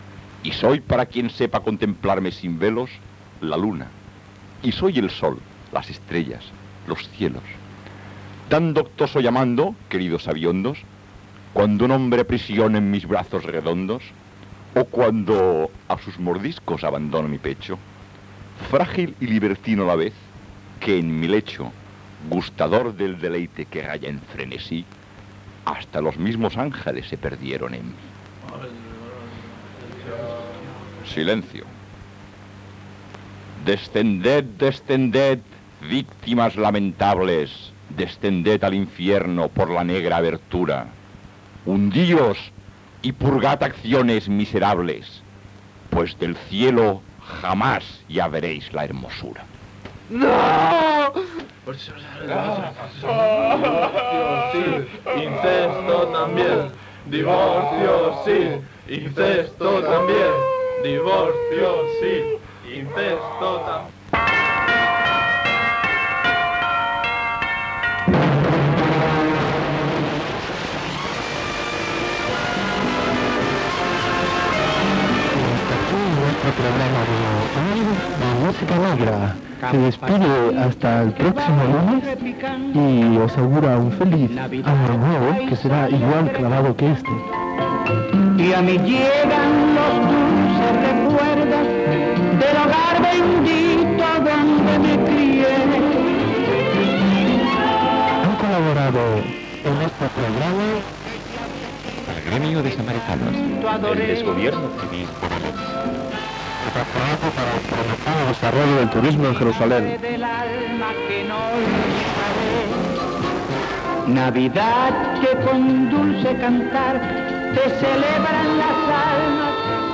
Fragment final d'una ficció sonora, comiat del programa "Música negra", identificació, informació de l'Associació de Veïns de la Vila de Gràcia sobre els lloguers.
FM